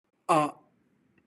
/or/